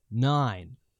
Voices / Male / 9.wav